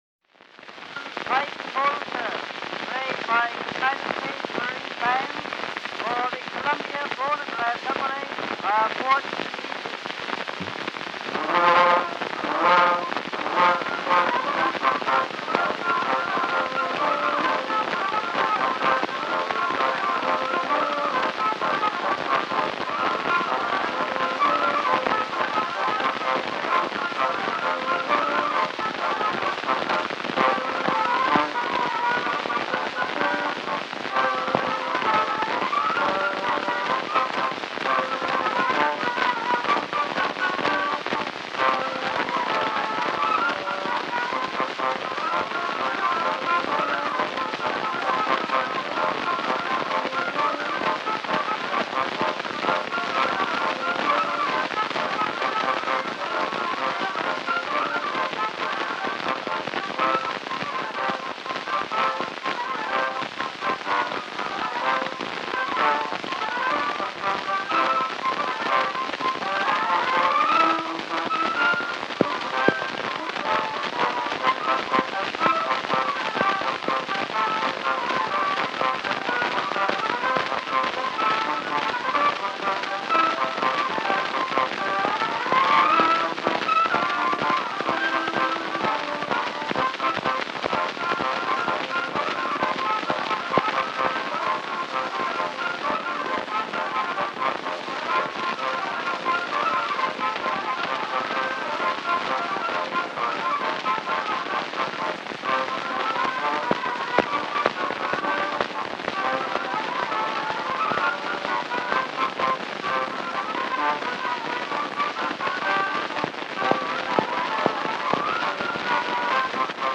Disc 1: Early Acoustic Recordings